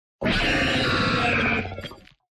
Cri de Chef-de-Fer dans Pokémon Écarlate et Violet.
Cri_1023_EV.ogg